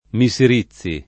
[ mi S ir &ZZ i ]